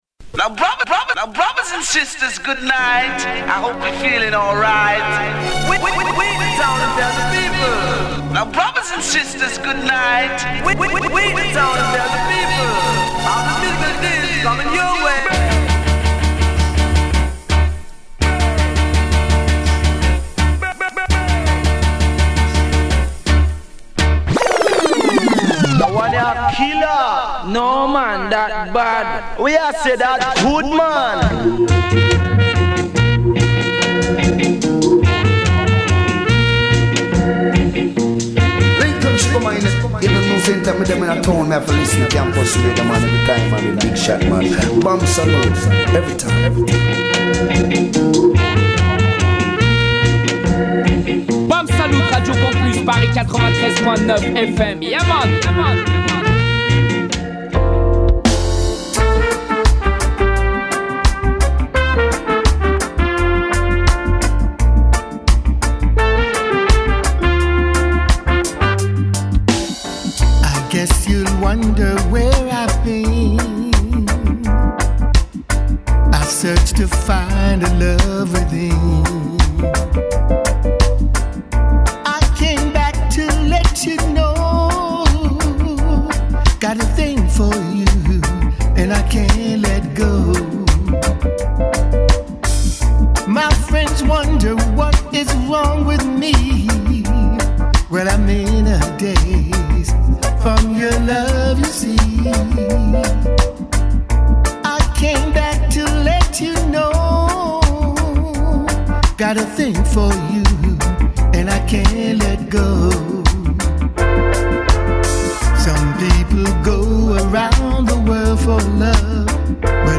des tunes, des reprises et des riddims